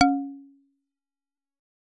Xyl_CSharp2.L.wav